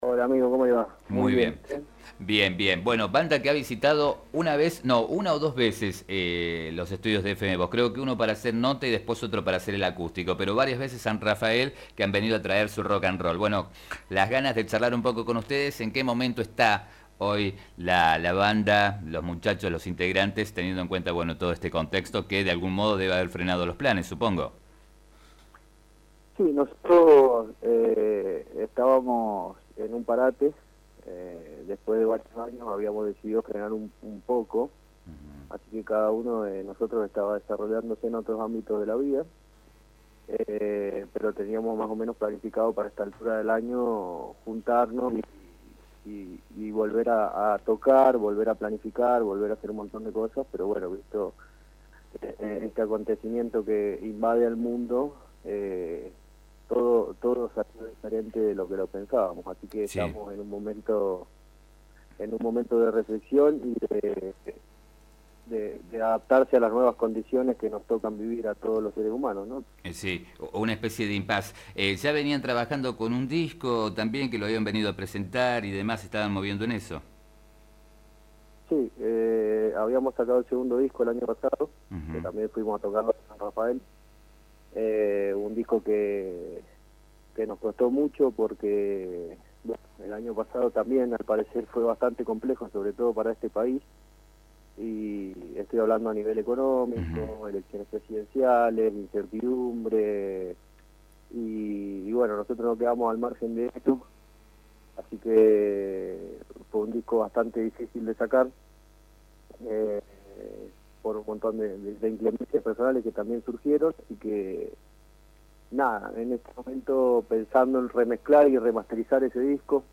Dialogó con FM Vos (94.5) y Diario San Rafael